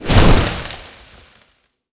nature_spell_vines_blast_impact3.wav